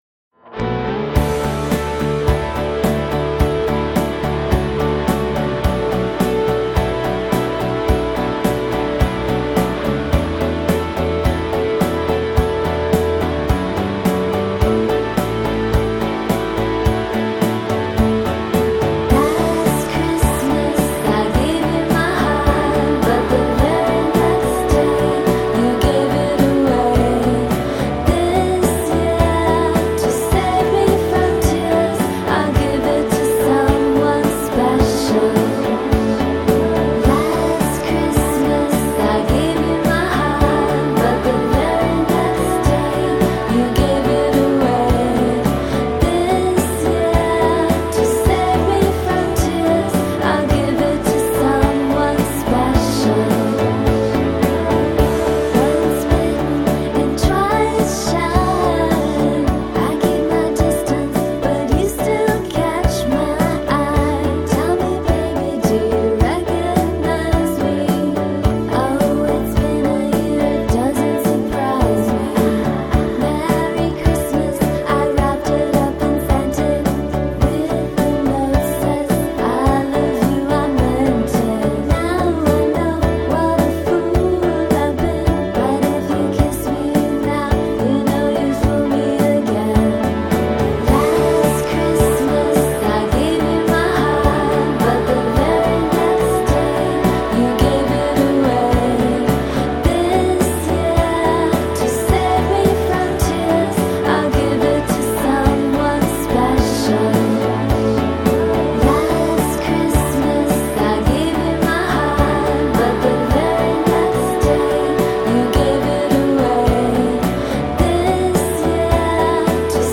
dream pop